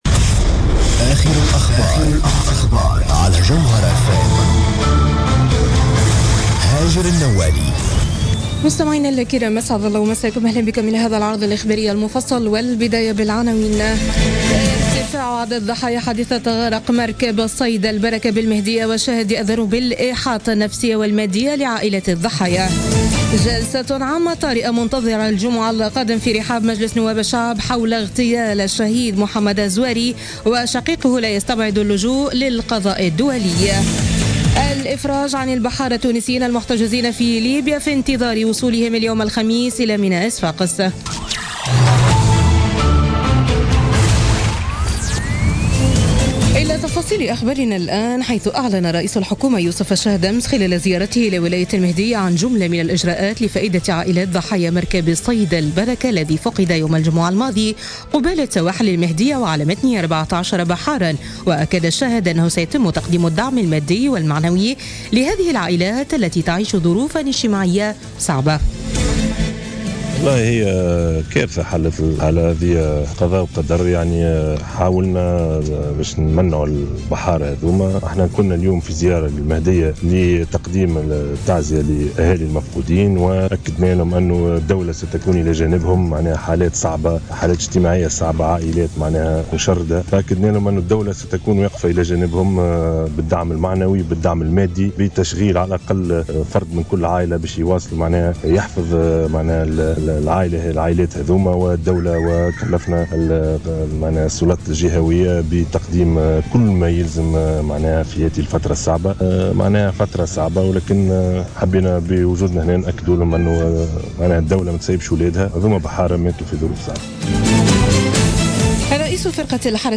نشرة أخبار منتصف الليل ليوم الخميس 22 ديسمبر 2016